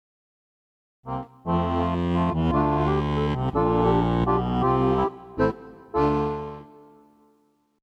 Nur der Form halber: des Rätsels Lösung ist das Volkslied Alle Vöglein sind schohon da . . . Gratulation zur frisch gestimmten Erika.